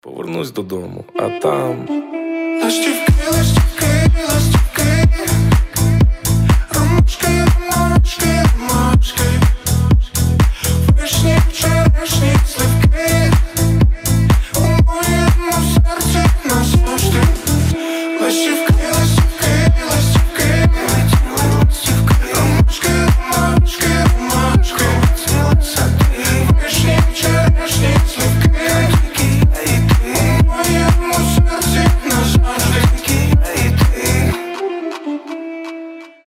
• Качество: 320, Stereo
украинские
добрые
ремиксы